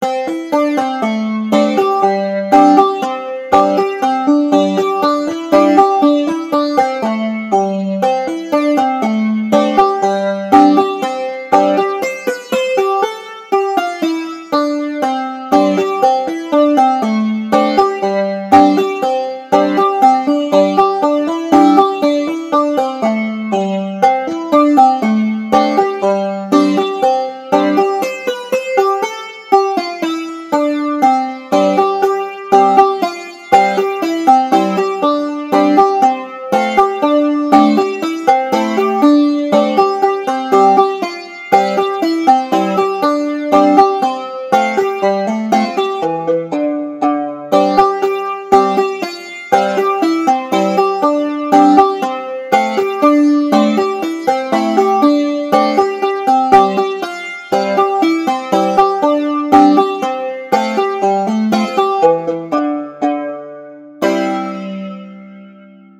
Hier habe ich einige Klangbeispiele zusammengestellt, welche teilweise mit Effekten versehen wurden.
ots-clawhamer-banjo-2.mp3